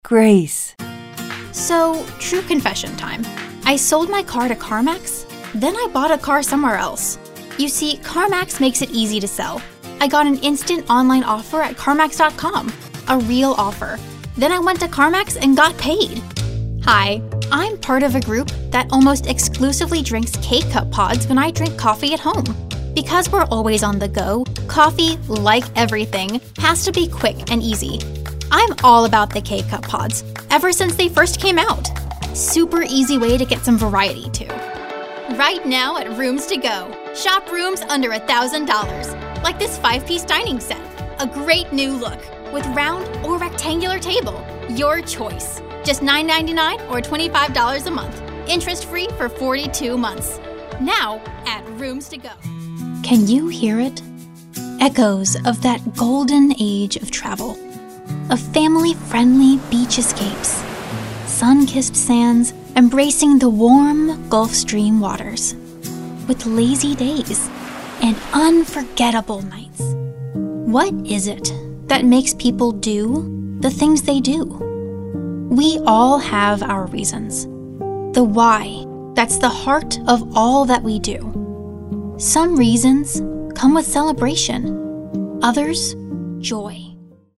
This young lady has big talent, and the perfect young-girl-next-door attitude.
english-showcase, young, young adult